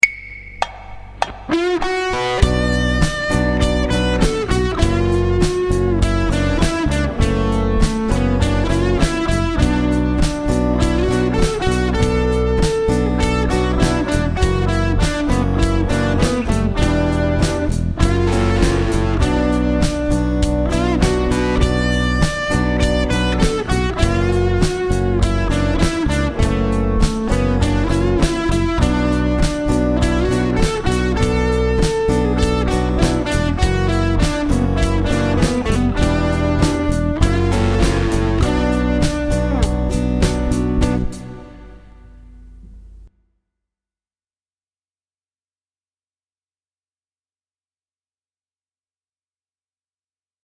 The difference is that B minor pentatonic is played over chords outlining the key of B minor while the D major pentatonic is played over chords in the key of D major.
B Minor Pentatonic Solo | Download
The B minor pentatonic scale has a sad and bluesy while the D major pentatonic scale has a happy country music sound.
Bminor_pent_solo.mp3